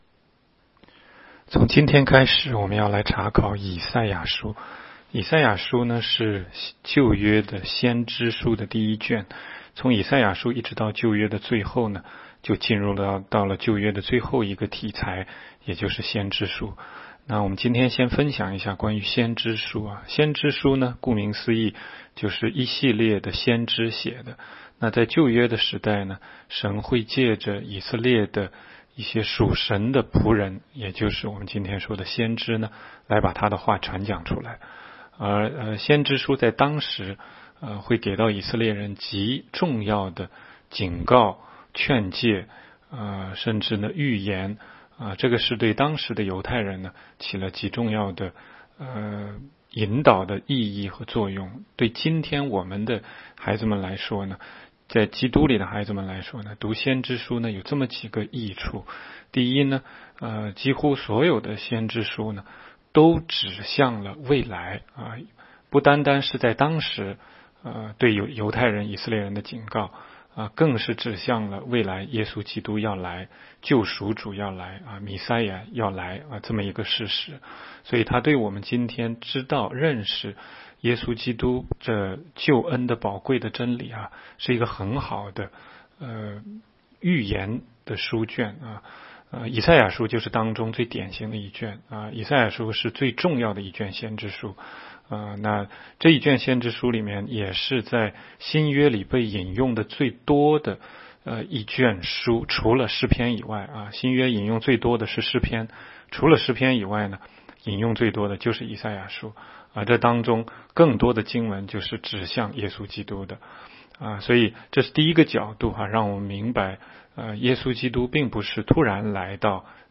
16街讲道录音 - 每日读经 -《 以赛亚书》1章